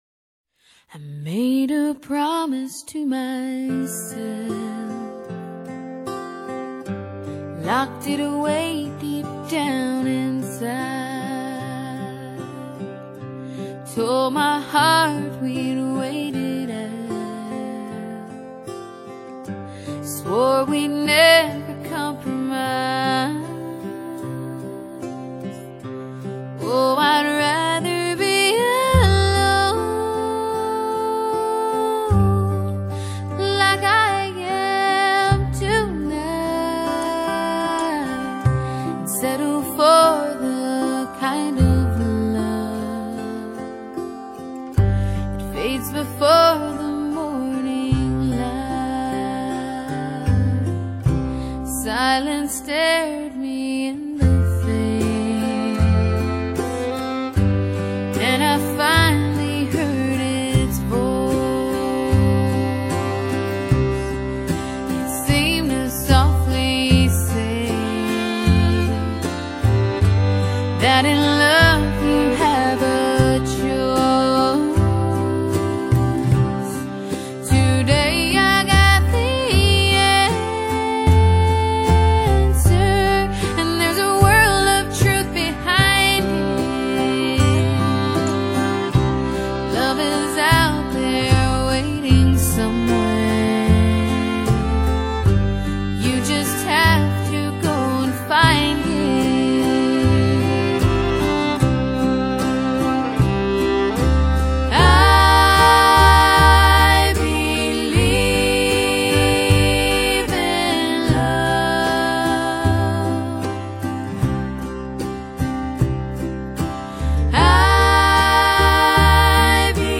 在抒情的旋律中唱出了对于纯真爱情的信念，透过吉他与小提琴的烘托
另外两人的和声也几乎天衣无缝，相当的讨好。